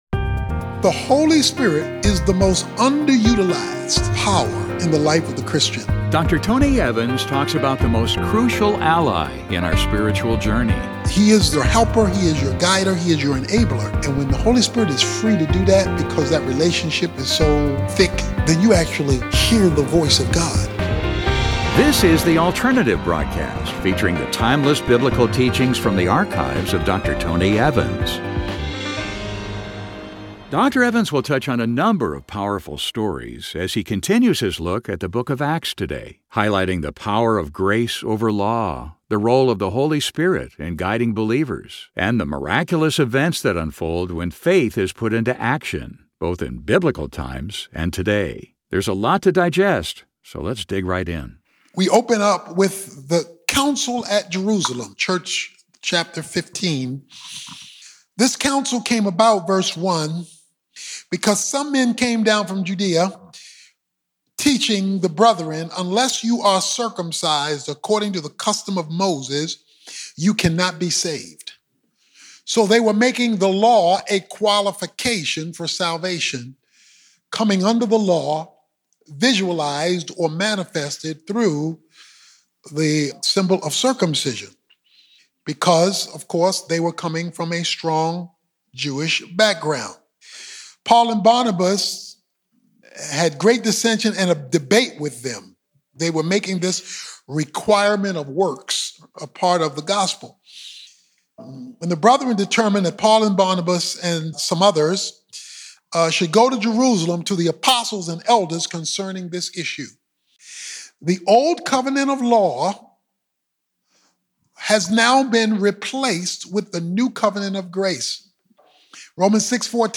In this message, Dr. Tony Evans unpacks these life-changing truths as he continues his journey through the book of Acts.